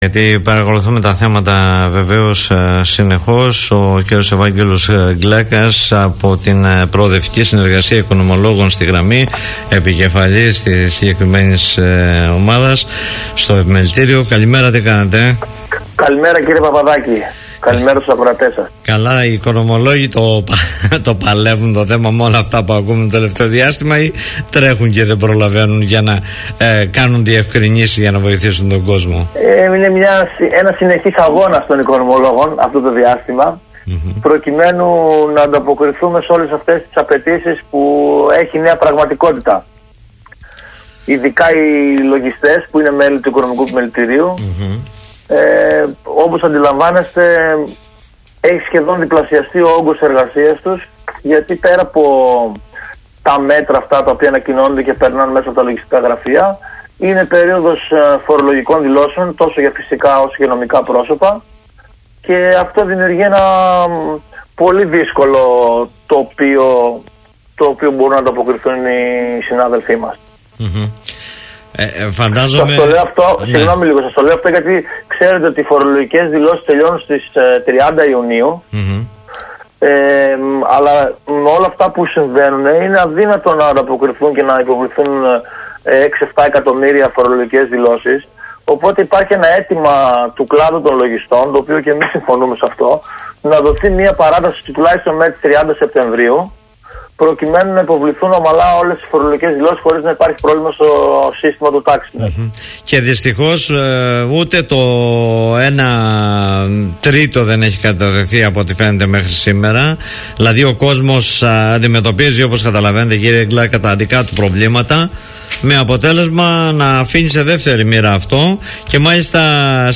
που μίλησε στην εκπομπή “Δημοσίως” στον politica 89.8